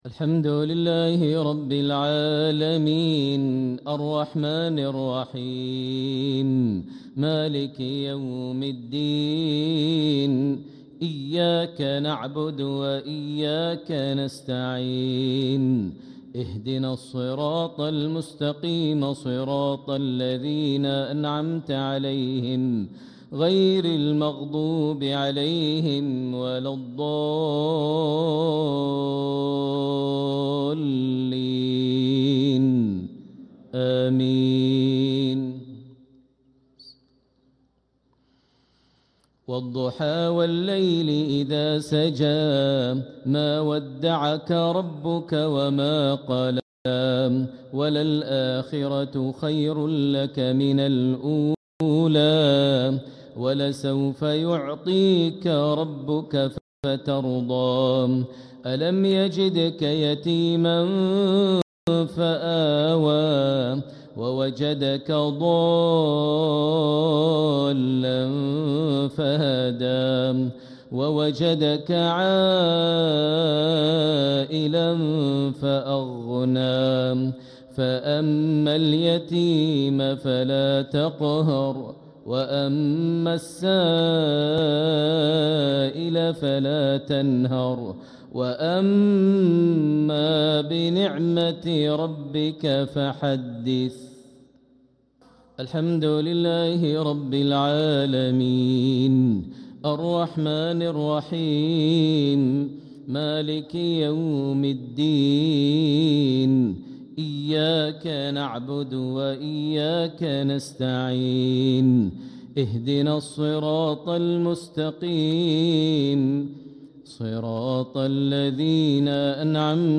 Reciter: Shiekh Maher Al-Muaiqly | Surah Ad-Duha | Surah Al-Kawthar